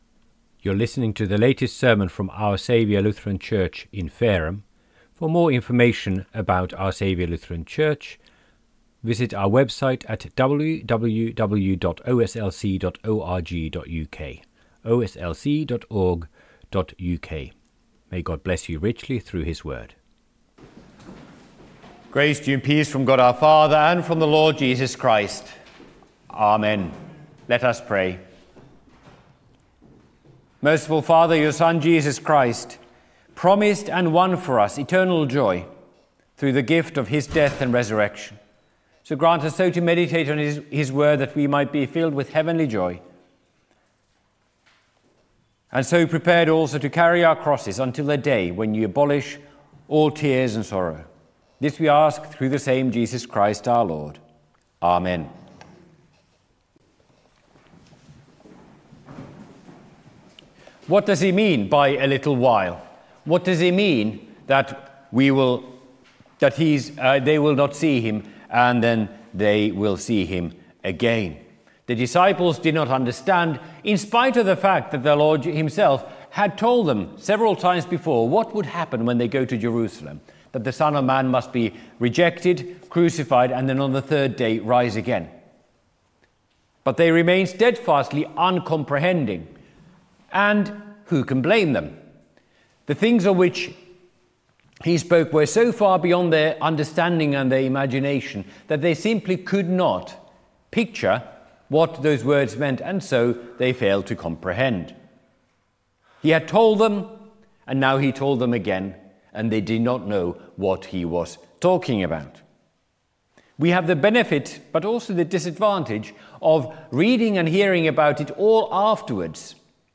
by admin | Apr 26, 2026 | Sermons, Easter, Jubilate